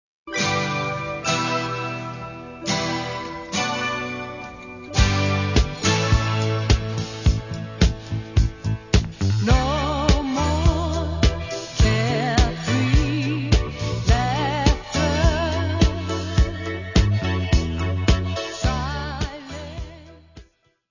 : stereo; 12 cm + folheto
Music Category/Genre:  Pop / Rock